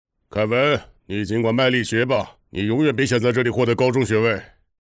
[综合样音集 – 男]
标签 卡通 写实 儿童 样音 生活 动画片 海外
【综合样音集 – 男】精选卡通、真人对话及旁白等多种男声类型，按角色分为老年、中年、青年、反派及胖男人等，表演风格从夸张到含蓄，从可爱到猥琐，从正义到邪恶，一应俱全。